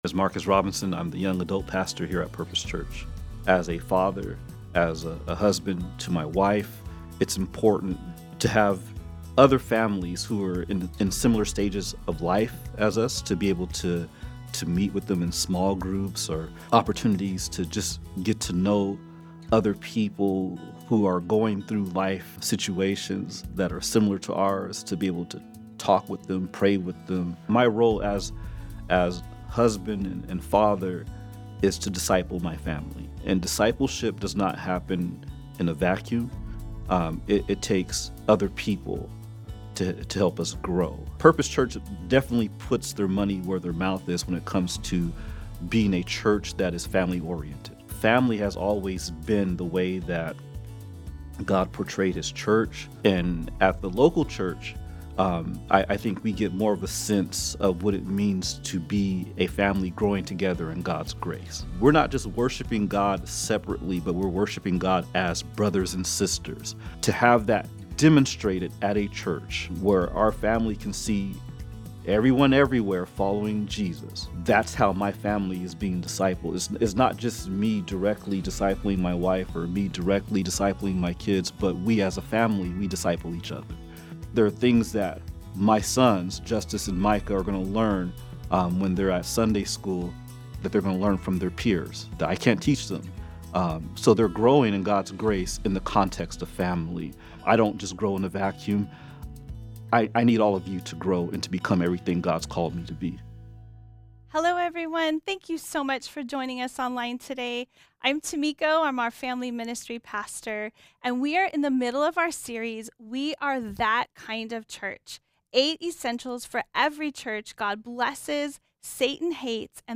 Study Guide | Download Audio File Traditional Worship (In-Person Service)